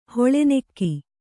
♪ hoḷe nekki